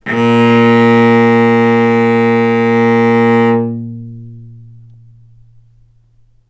vc-A#2-ff.AIF